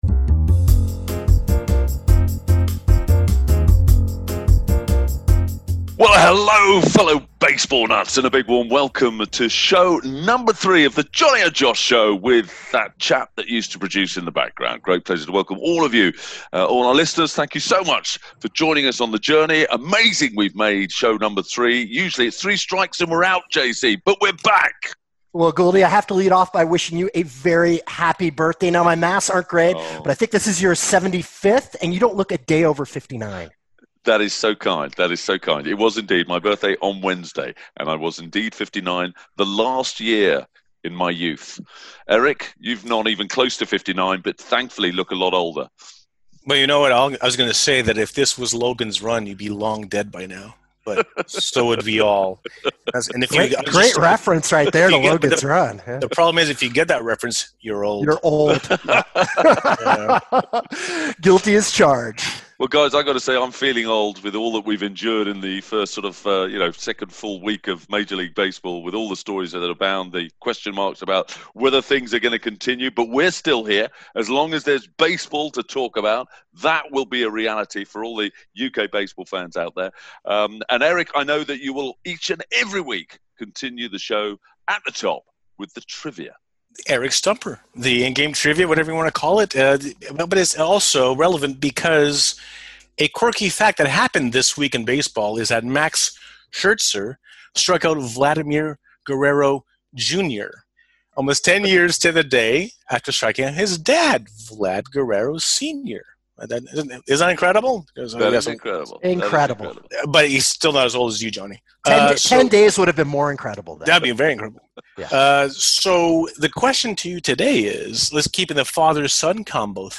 Bill Lee interview